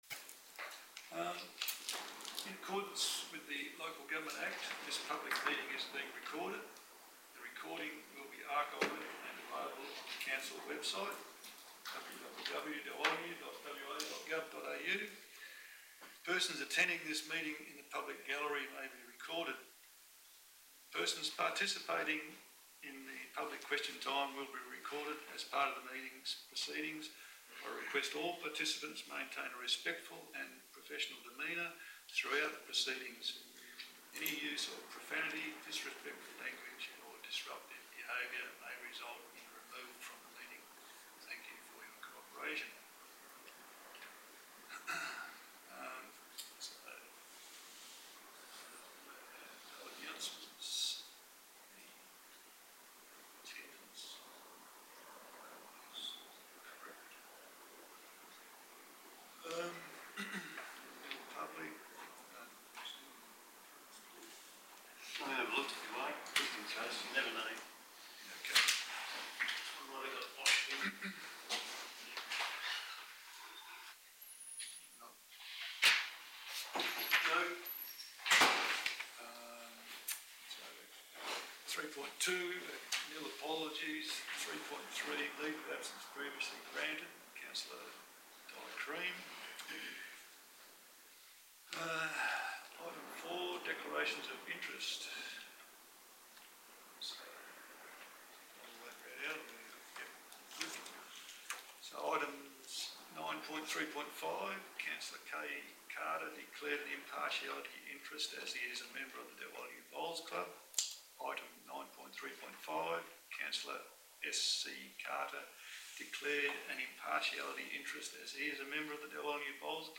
Ordinary Council Meeting - 24 February 2026 » Shire of Dalwallinu